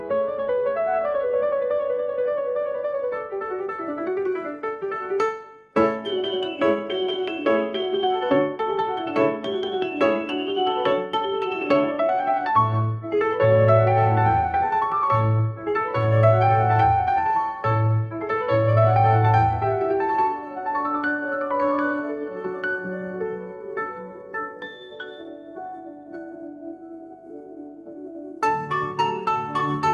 Stumbling around the web looking for things to AI Generate, and came across "MusicGPT" which makes use of the Meta MusicGen found above.
8-bit Piano Concerto
8-bit_Piano_Concerto_-_Small_mp3.mp3